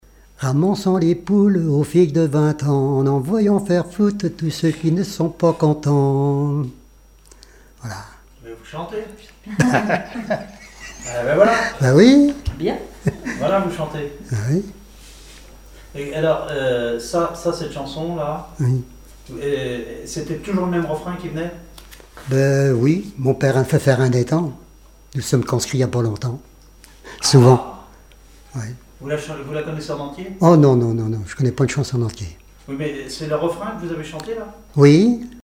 Mémoires et Patrimoines vivants - RaddO est une base de données d'archives iconographiques et sonores.
Chants brefs - Conscription
Pièce musicale inédite